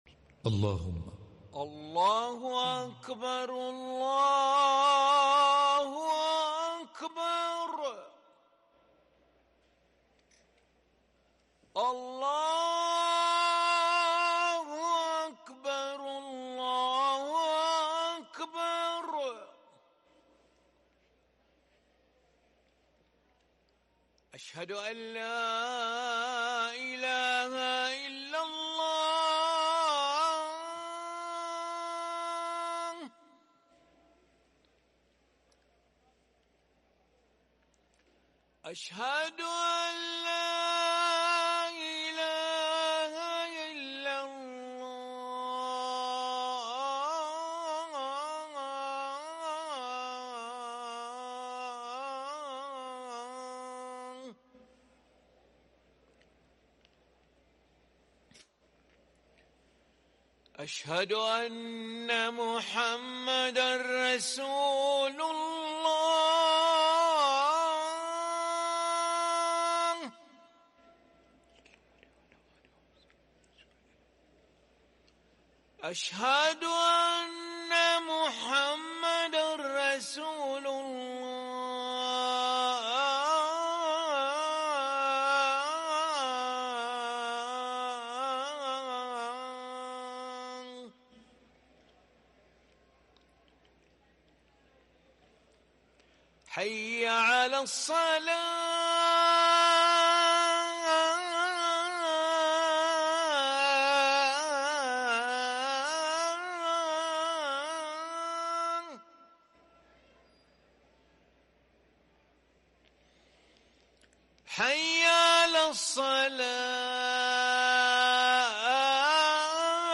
أذان العشاء للمؤذن علي ملا الخميس 26 صفر 1444هـ > ١٤٤٤ 🕋 > ركن الأذان 🕋 > المزيد - تلاوات الحرمين